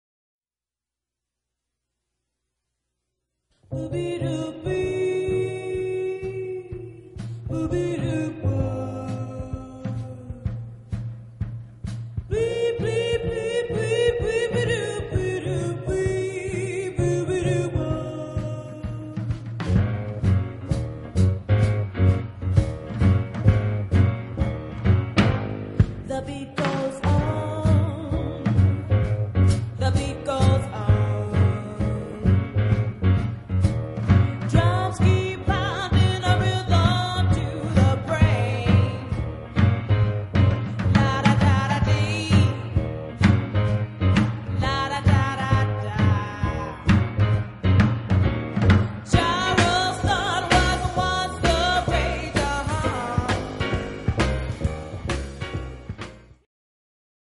ストリングベース+ピアノ